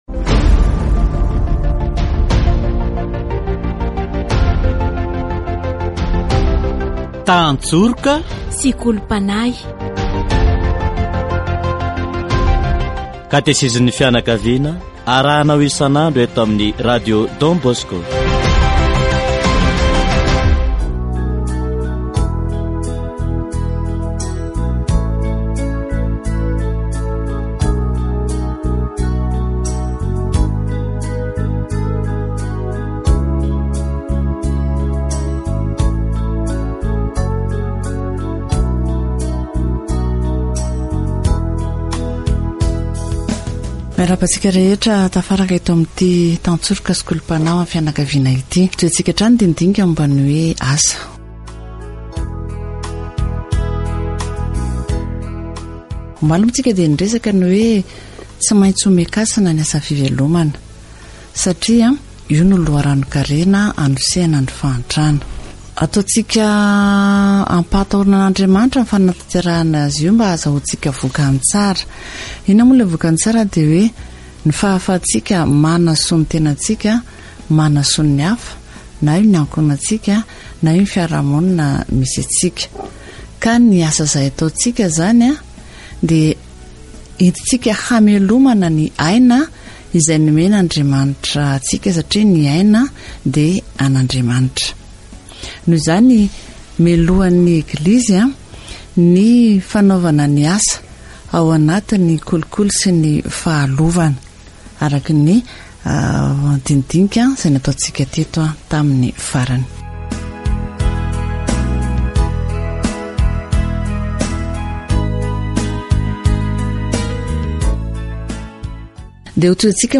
Le repos est nécessaire car c'est pour se souvenir de l'œuvre de Dieu, dans la création du monde.  Catéchèse sur le travail